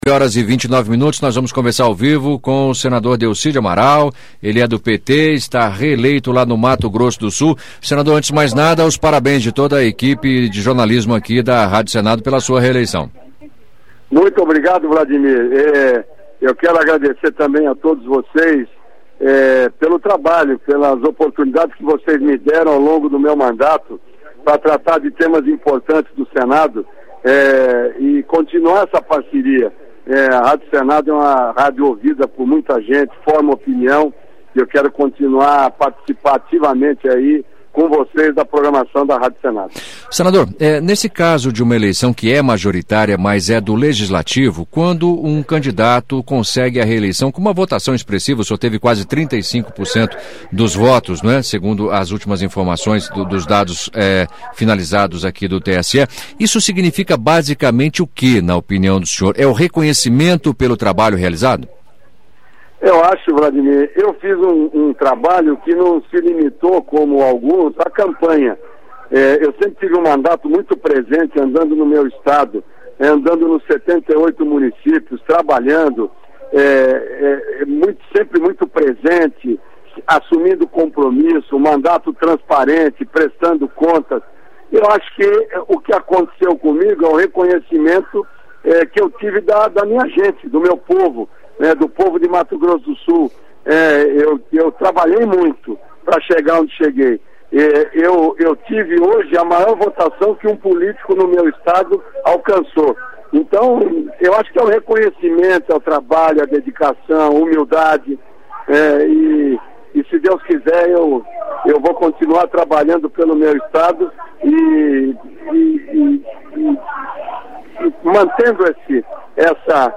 Entrevista com o senador reeleito Delcídio Amaral (PT-MS)